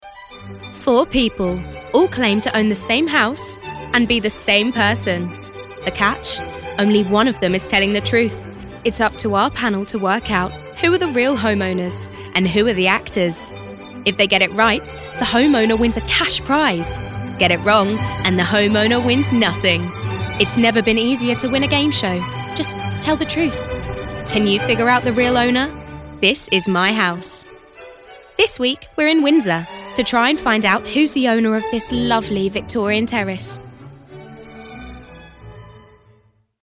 Documentary Showreel
a warm, playful and clear-toned voice, with an abundance of accents and characters to play with!
Female
Neutral British